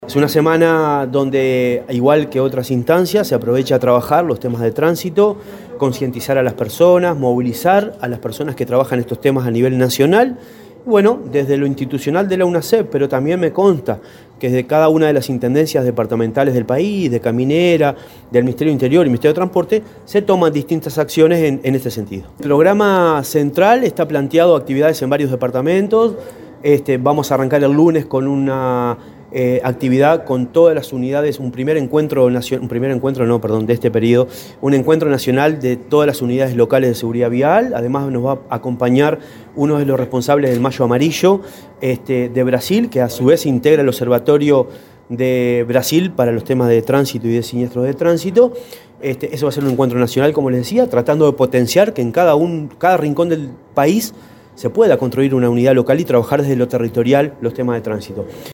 El presidente de la Unasev, Marcelo Metediera, se refirió al proyecto de implementar la educación vial en escuelas y centros de enseñanza media.